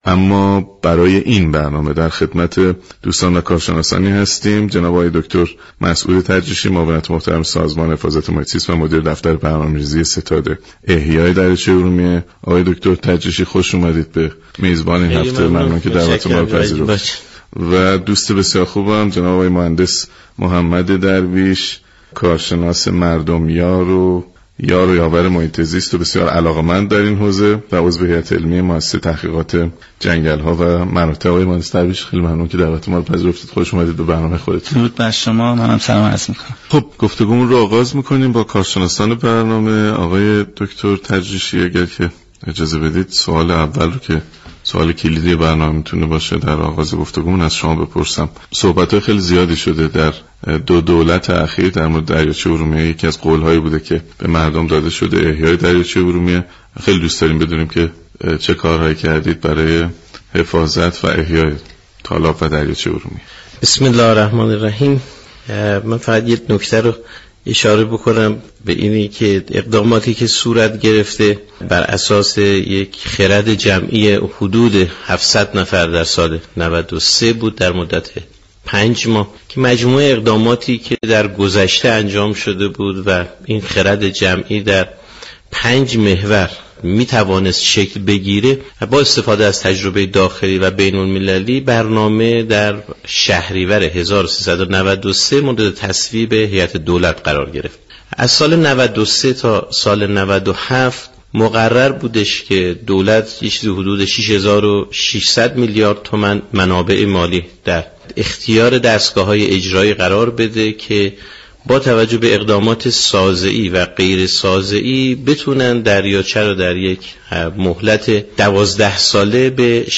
گفت و گوی رادیویی